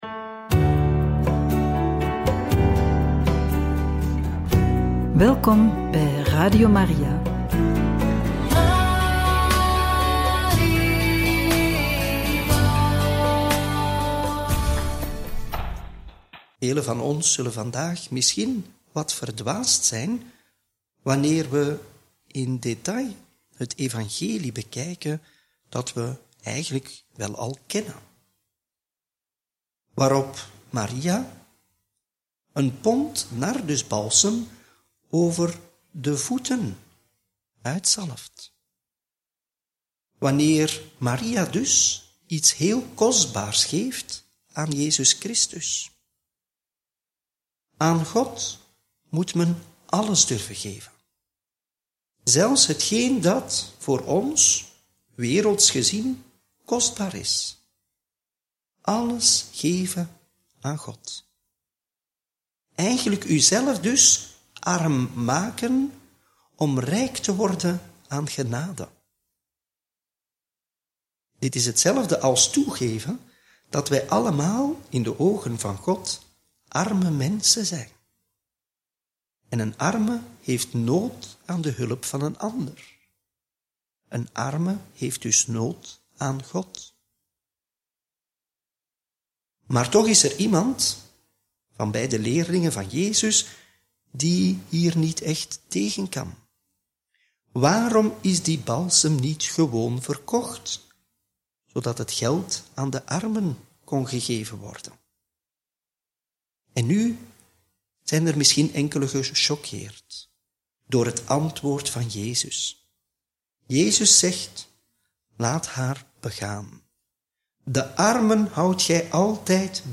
Homilie bij het Evangelie van maandag 14 april 2025 – Joh. 12, 1-11